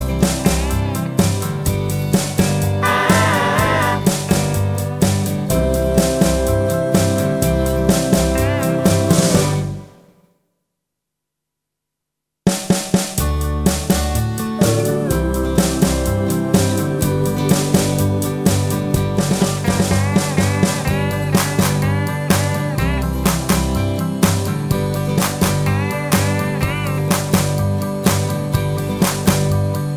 No Saxophone Pop (1960s) 2:22 Buy £1.50